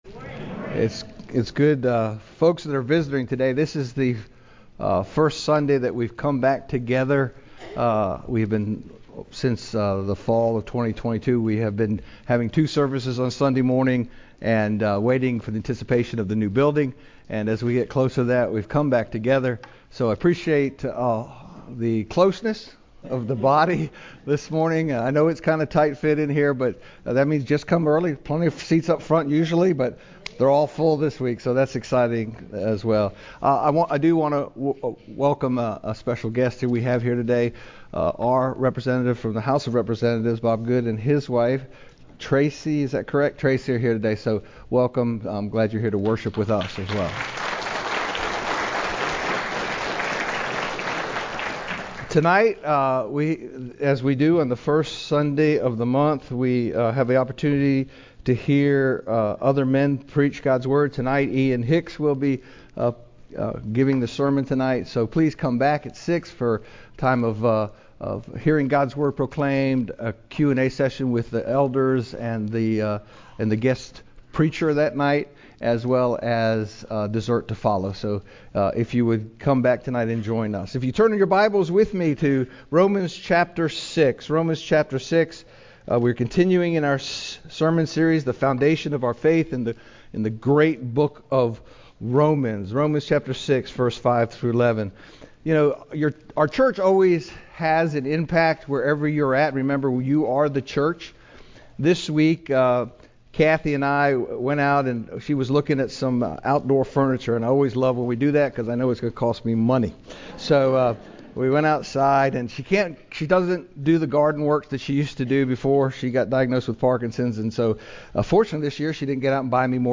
Sermon
GHBC-Service-Dead-To-Sin-Romans-65-11-CD.mp3